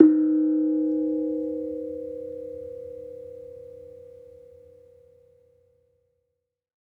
Kenong-resonant-D#3-f.wav